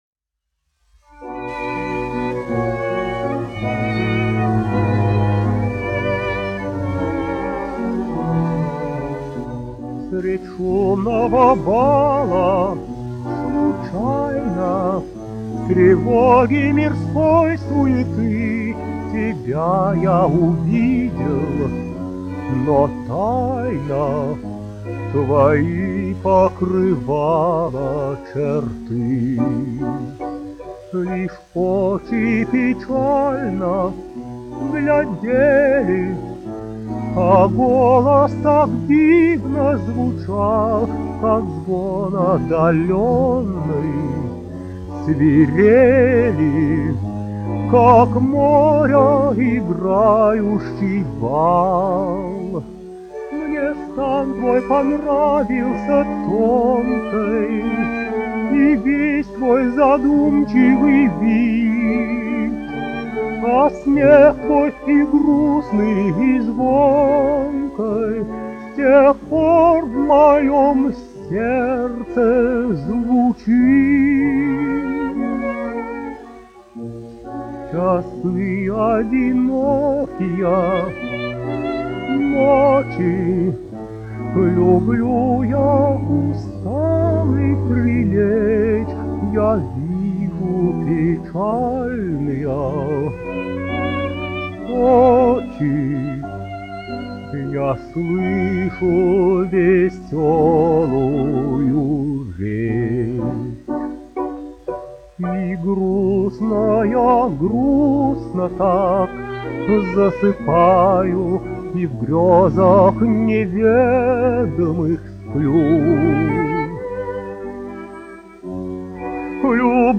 1 skpl. : analogs, 78 apgr/min, mono ; 25 cm
Romances (mūzika)
Dziesmas (vidēja balss)
Skaņuplate